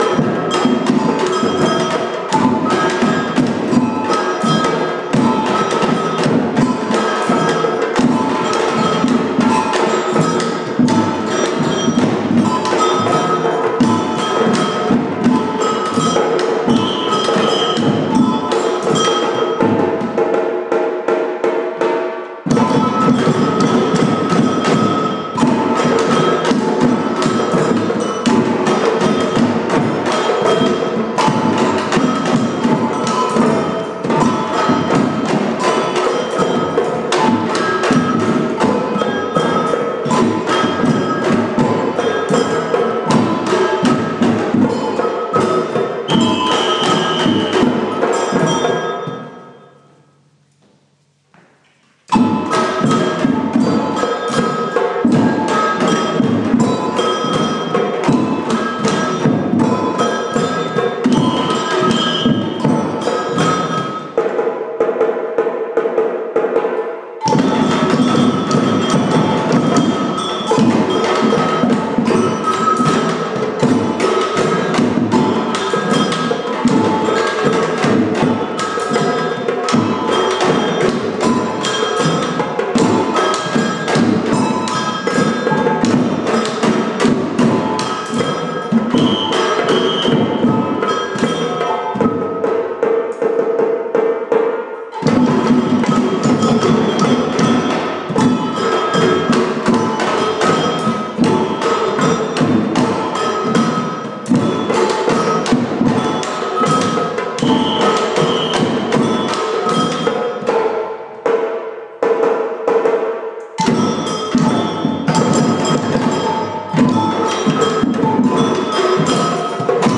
Call and response.
Samba drumming. Listen out for the call and response!